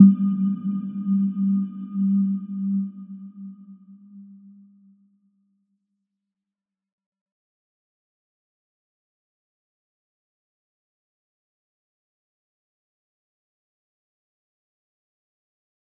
Little-Pluck-G3-mf.wav